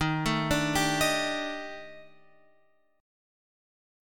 D# Major 7th Flat 5th